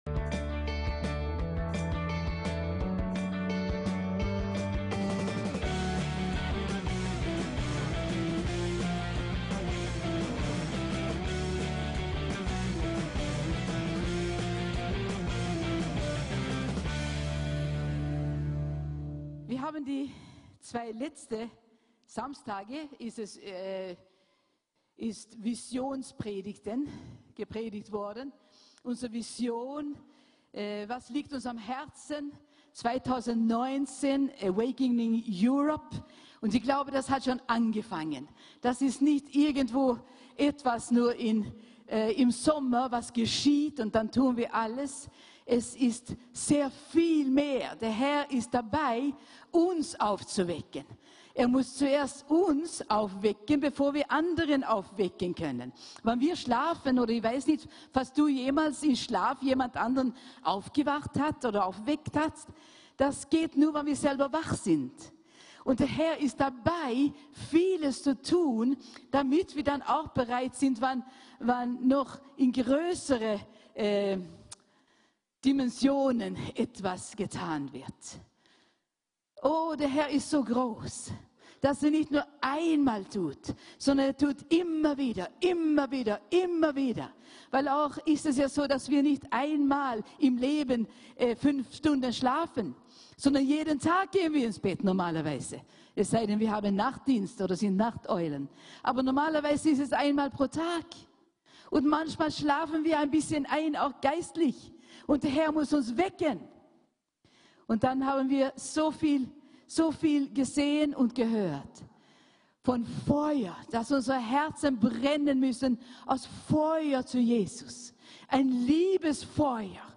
VISIONS -PREDIGT